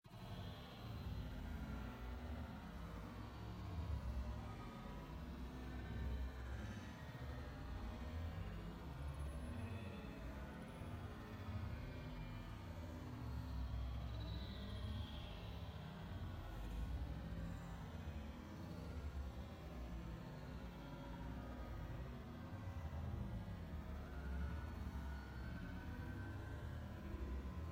harvardyard_scene.ogg